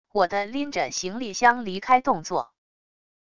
我的拎着行李箱离开动作wav音频